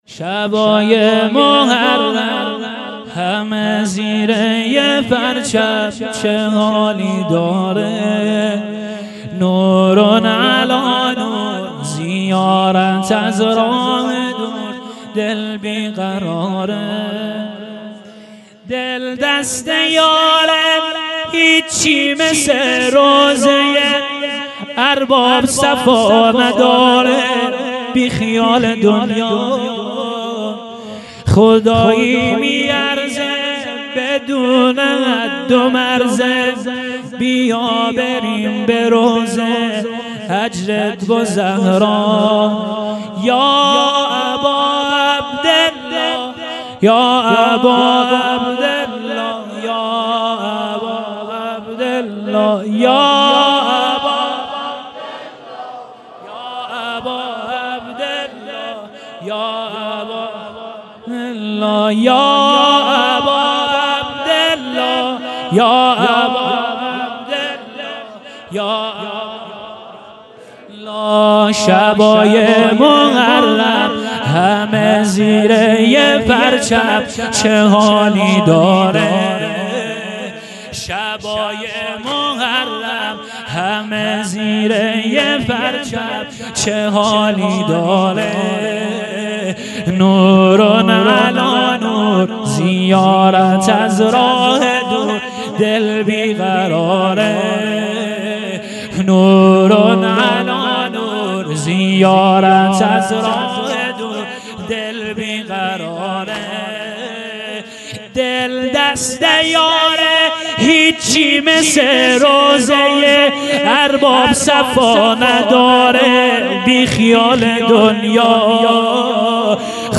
واحد سنگین احساسی